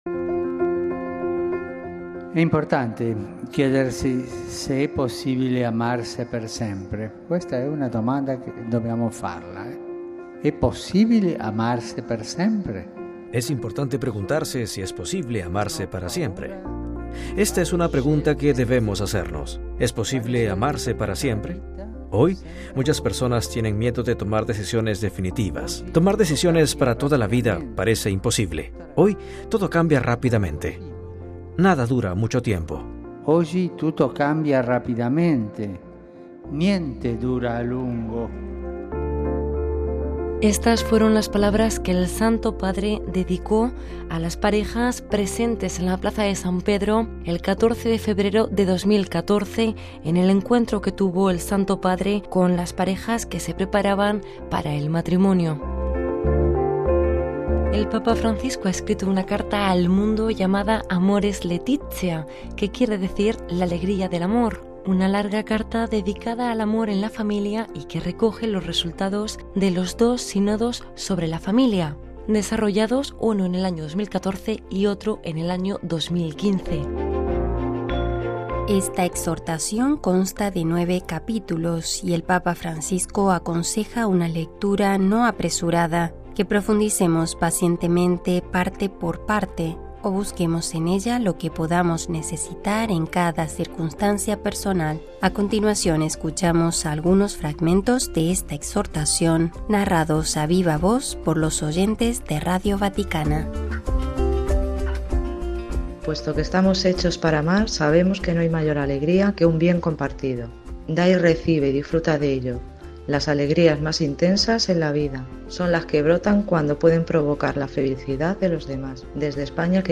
A continuación, citamos algunos fragmentos de esta exhortación, narrados a viva voz por los oyentes de Radio Vaticana